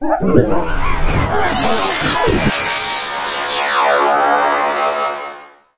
形态变换的声音效果 15
描述：从ZOOM H6录音机和麦克风Oktava MK01201的现场录制的效果，然后进行处理。
Tag: 未来 托管架 无人驾驶飞机 金属制品 金属 过渡 变形 可怕 破坏 背景 游戏 黑暗 电影 上升 恐怖 开口 命中 噪声 转化 科幻 变压器 冲击 移动时 毛刺 woosh 抽象 气氛